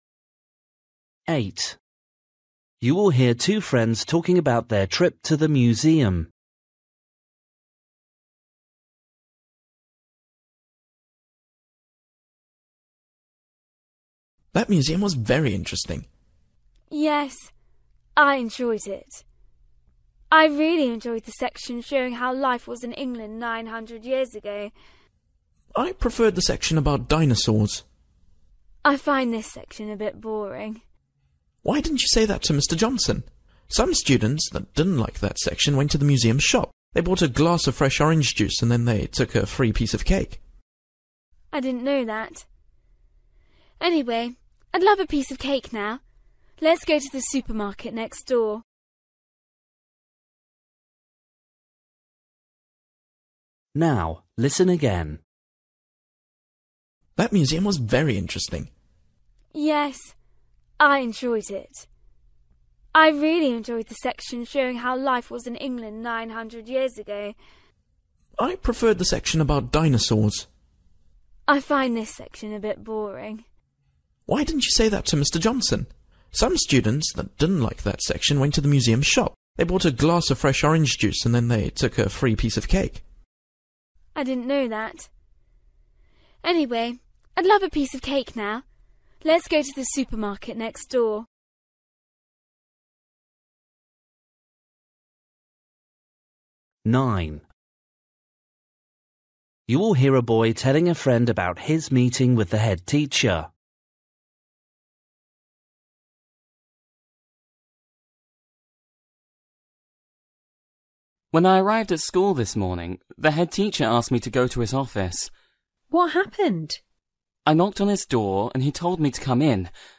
Listening: everyday short conversations
8   You will hear two friends talking about their trip to the museum. The girl isn’t interested in
9   You will hear a boy telling a friend about his meeting with the headteacher. The headteacher and the history teacher
12   You will hear two friends talking about their day at the zoo. What was the girl scared by?